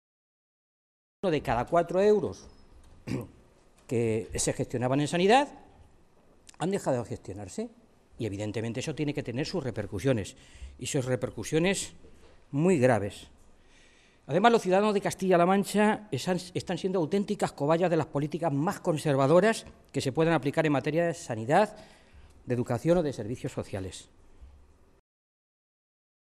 El portavoz de Sanidad del Grupo Socialista, Fernando Mora, ha comparecido hoy ante los medios de comunicación en Toledo para hablar de lo que ha calificado como “el Plan de Deterioro de los servicios sociales” puesto en marcha por María Dolores De Cospedal y que, a su juicio, está teniendo una especial incidencia en la Sanidad pública regional.
Cortes de audio de la rueda de prensa